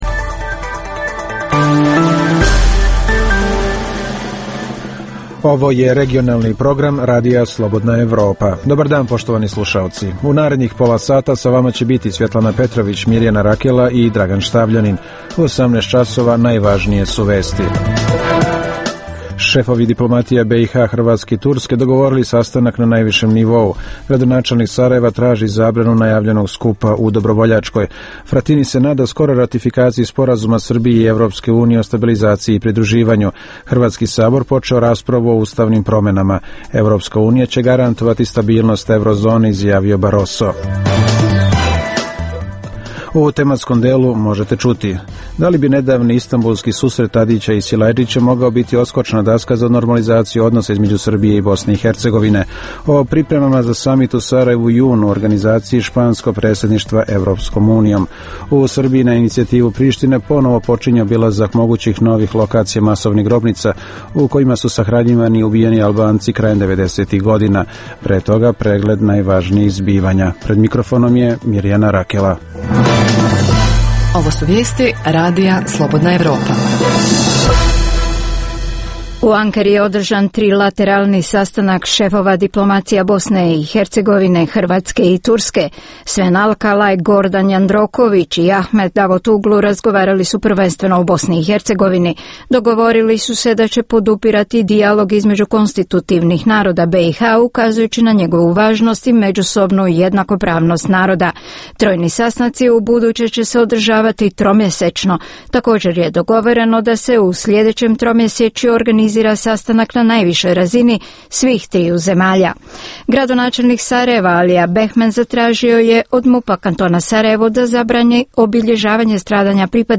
Emisija o dešavanjima u regionu (BiH, Srbija, Kosovo, Crna Gora, Hrvatska) i svijetu. Prvih pola sata emisije sadrži najaktuelnije i najzanimljivije priče o dešavanjima u zemljama regiona i u svijetu (politika, ekonomija i slično).
Reportaže iz svakodnevnog života ljudi su svakodnevno takođe sastavni dio “Dokumenata dana”.